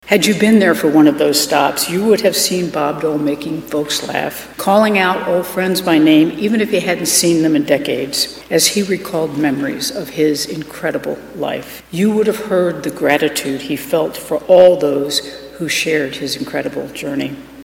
Governor Laura Kelly started her speech thanking current and former Kansas senators, saying they are following in the foot prints of Dole.